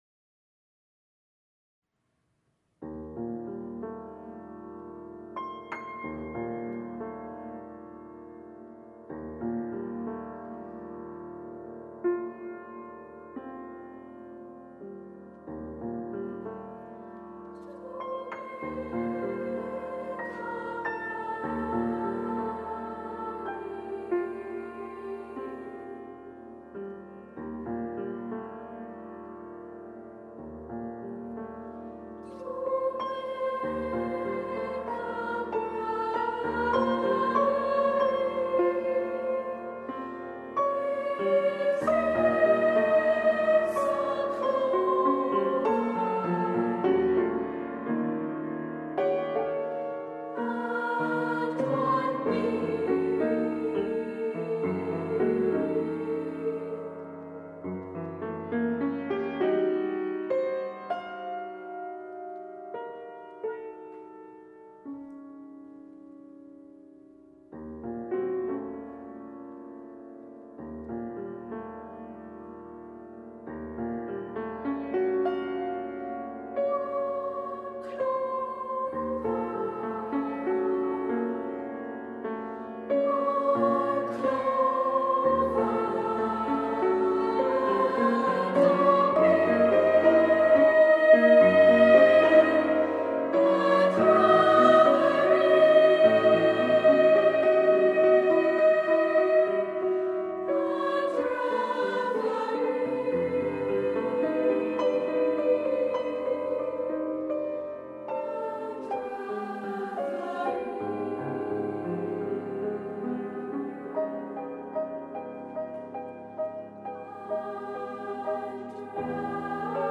SSA, piano
With ethereal vocal lines and a dreamlike piano part